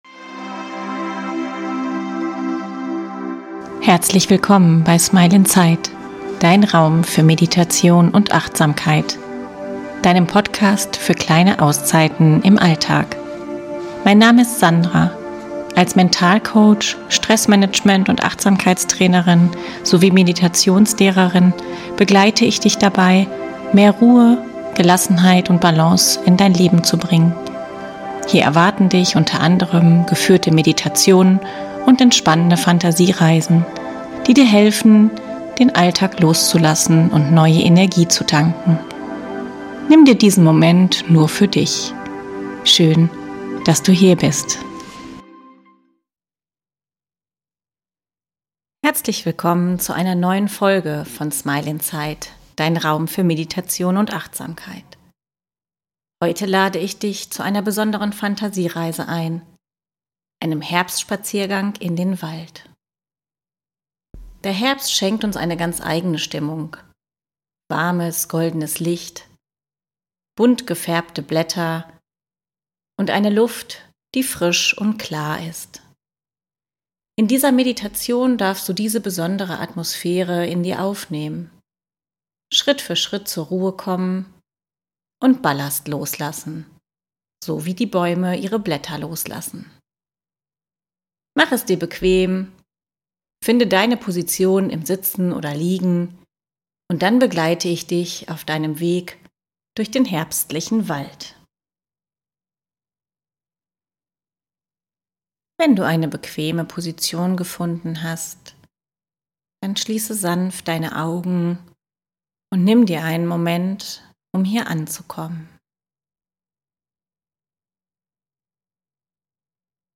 In dieser Fantasiereise nehme ich dich mit auf einen Spaziergang durch einen bunten, lichtdurchfluteten Herbstwald.
Du hörst das Rascheln der Blätter, spürst die klare Luft und erlebst, wie die Natur Schritt für Schritt zur Ruhe kommt. So wie die Bäume ihre Blätter loslassen, darfst auch du Anspannung und Belastungen abgeben.